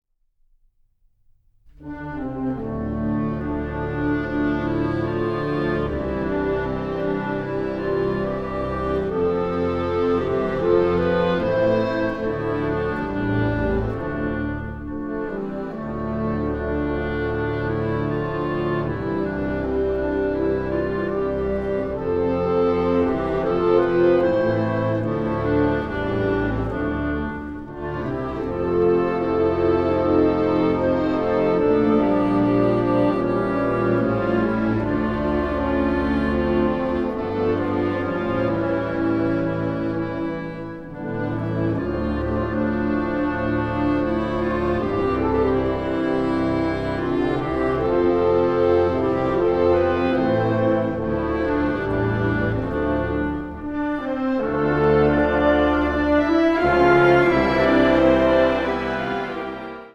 Categorie Harmonie/Fanfare/Brass-orkest
Subcategorie Concertmuziek
Bezetting Ha (harmonieorkest); [ (optional); Org (Orgel); ]